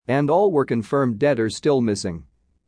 母音
半母音